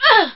f_outch03.ogg